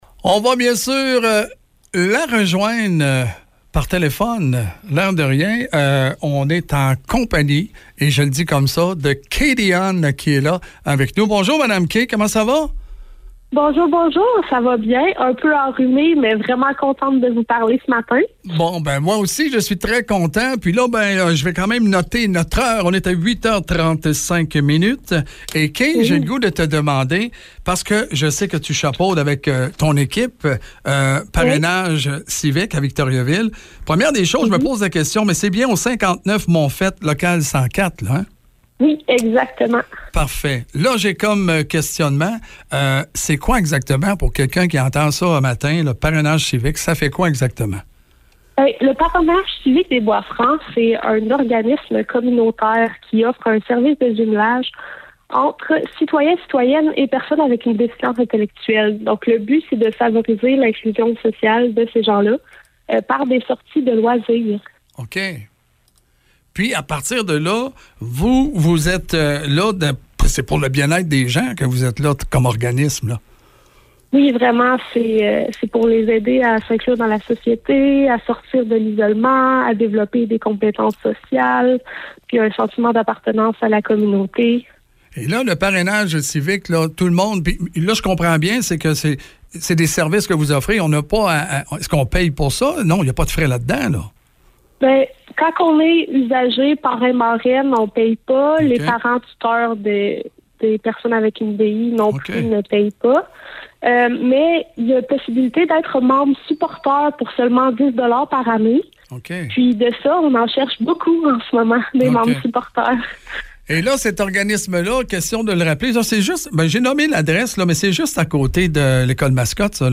ENTREVUES VIVA 101,9 – 11 septembre 2025* *Addendum : L es seules personnes qui ne paient pas de frais d’adhésion sont les filleul·es et les parrains/marraines.